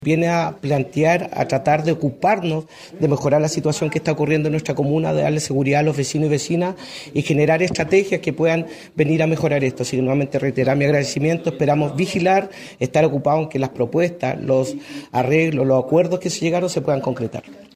El alcalde suplente, Pedro Barría, manifestó que espera que los acuerdos se concreten por el bien de la comunidad.